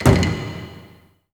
A#3 DRUMS0ER.wav